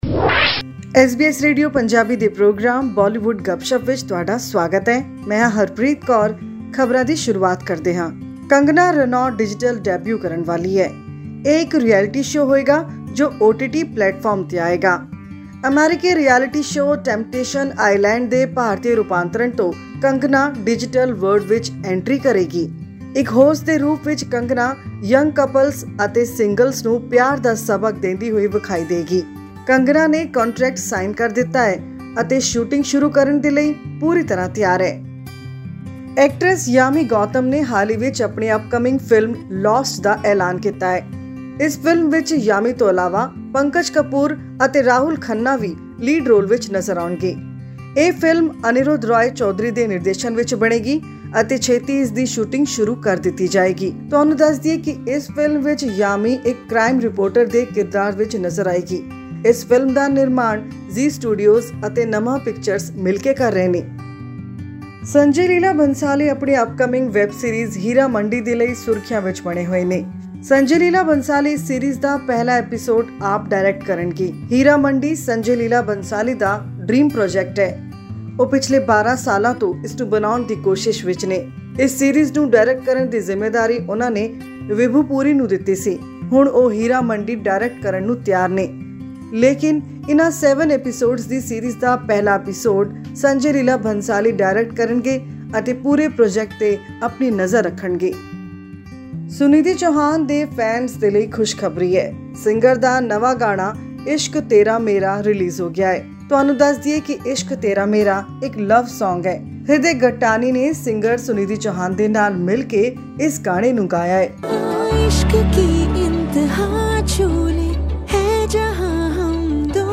Filmmaker Sanjay Leela Bhansali is all set to direct the first episode of his ambitious web series ‘Heera Mandi’. But why it took him twelve long years to start this dream project, listen to the details in our weekly news bulletin from Bollywood.